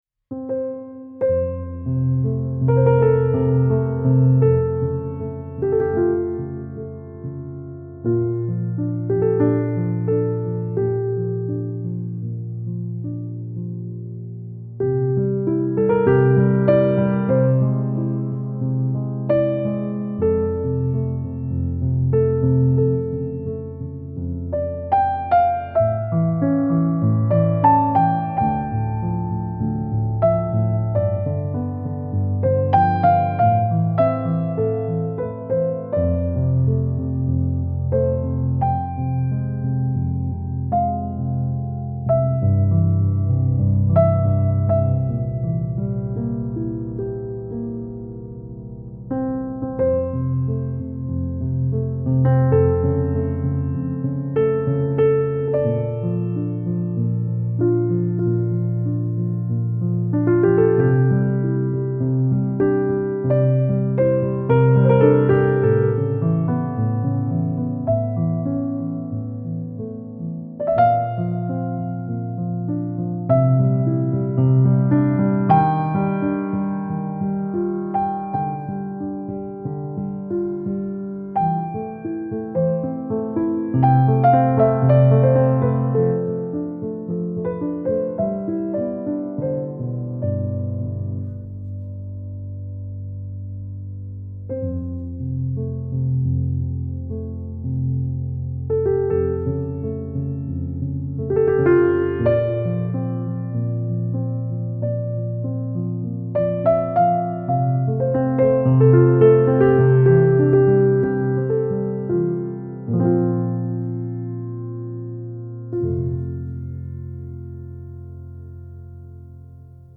سبک آرامش بخش , پیانو , عصر جدید , موسیقی بی کلام